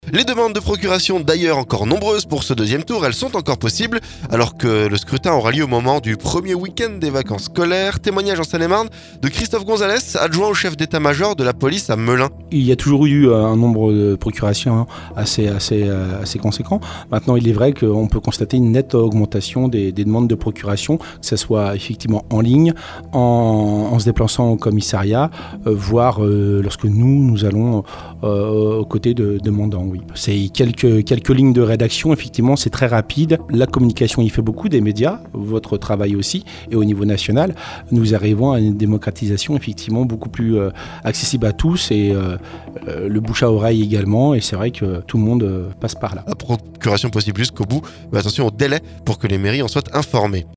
Alors que le deuxième tour a lieu au moment du premier week-end des vacances scolaires. Témoignage en Seine-et-Marne